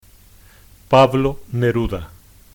Pablo Neruda (/nəˈrdə/ nə-ROO-də;[1] Spanish pronunciation: [ˈpaβlo neˈɾuða]